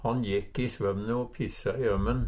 i svømne - Numedalsmål (en-US)